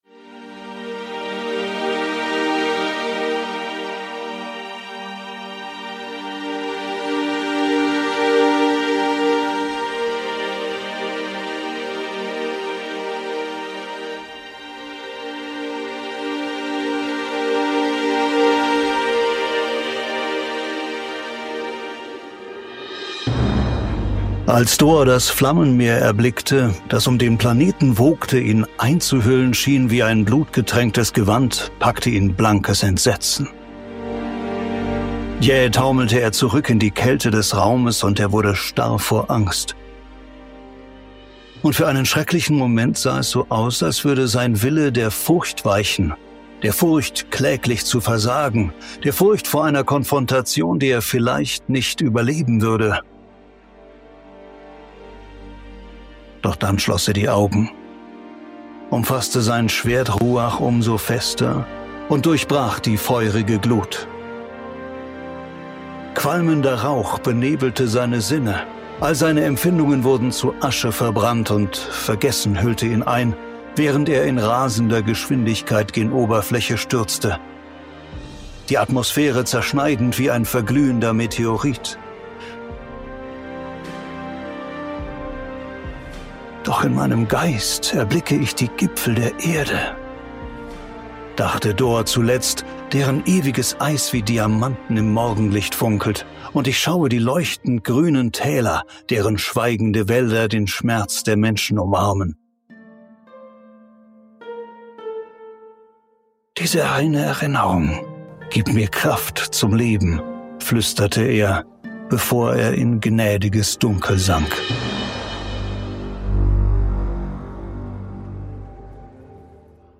Und der Outtake der Sendung am Ende lohnt sich auf jeden Fall :-) Mehr